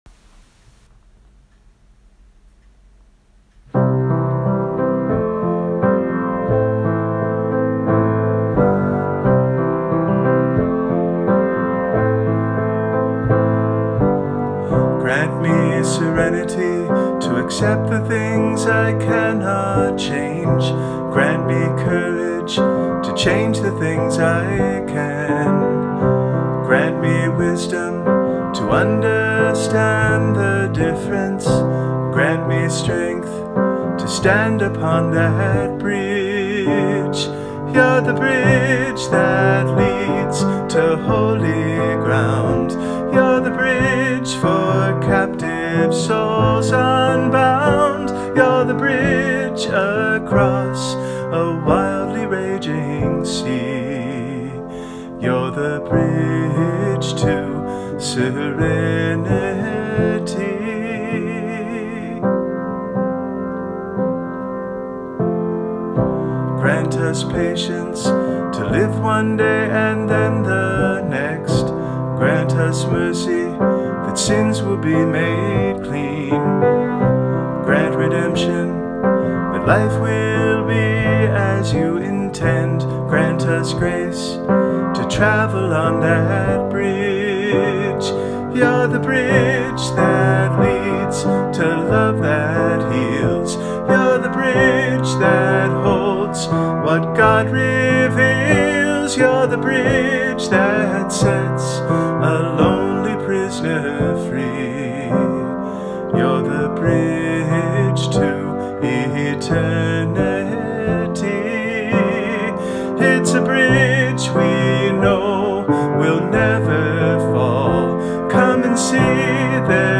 I recorded the song hastily this morning on my iPhone.  Please pardon the poor quality of the recording and my pitchiness. I felt a sense of urgency about sharing the song with you just as it is, even in its unfinished and unpolished state.